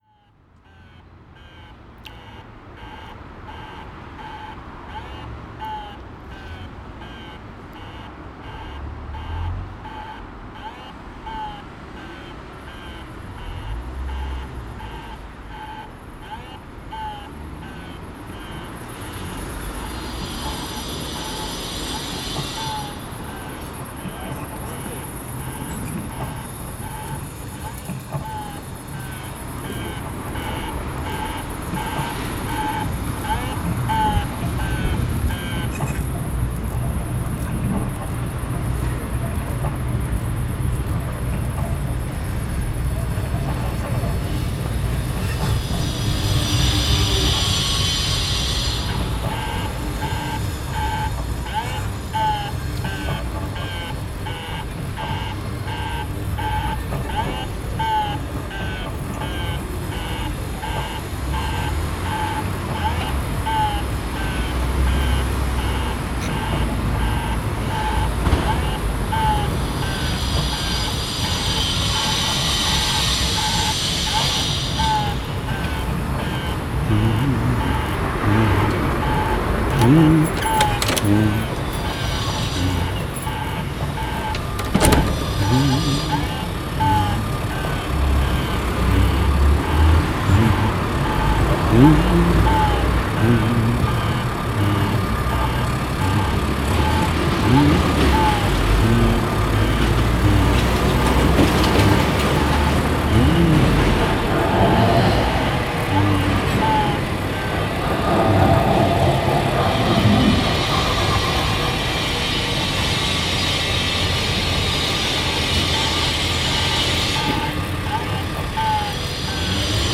field recordings, sound art, radio, sound walks
Tagy: stroje hlas doprava
Jak jsem kráčel Kamenickou směrem k Veletržní míjel jsem podivný naléhavý kvákavý zvuk. Zněl odněkud zhůry.
Když jsem zvedl pohled uvědomil jsem si, že to je nejspíš rozbitý alarm.